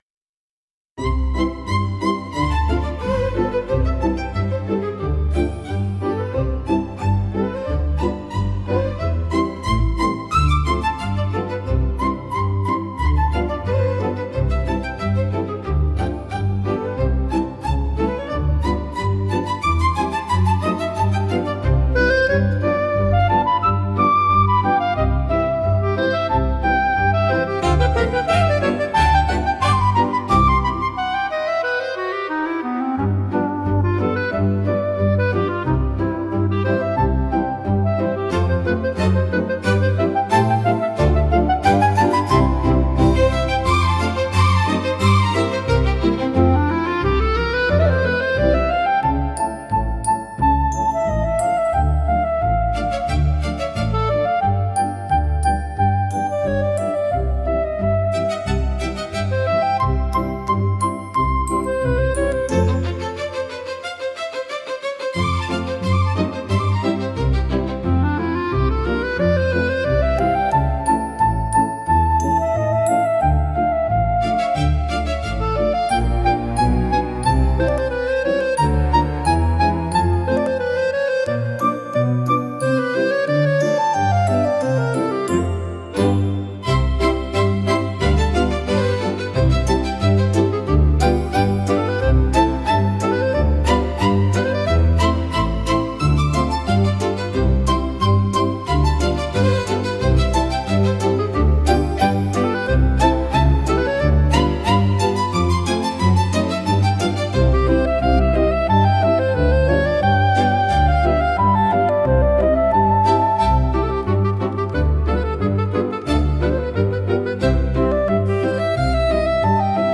聴く人に高雅さや荘厳さ、心の深みを感じさせる普遍的なジャンルです。